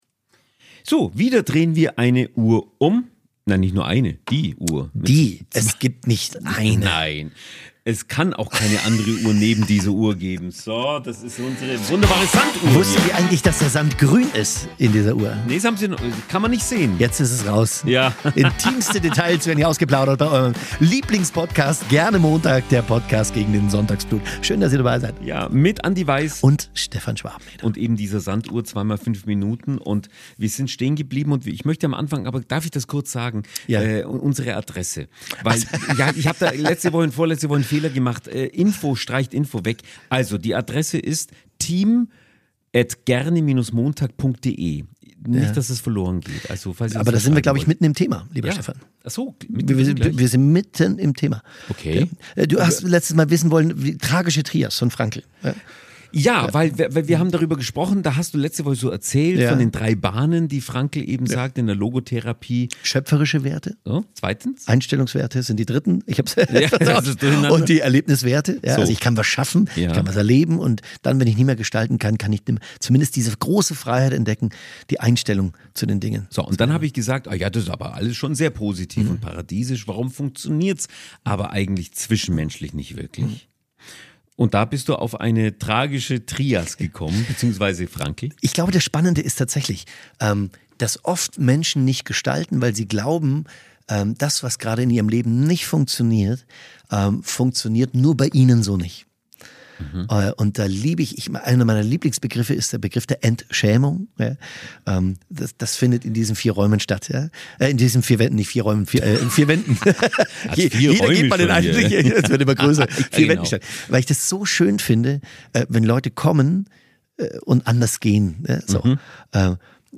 Ein Kerngedanke dieser Folge: Der Mensch hat immer die Freiheit, Leid in Leistung, Schuld in Wiedergutmachung und begrenzte Lebenszeit in verantwortliches Handeln zu verwandeln. Diese Episode berührt so sehr, dass wir am Ende sogar auf unsere Themamusik verzichten – und ihr werdet bestimmt noch lange über die Inhalte nachdenken.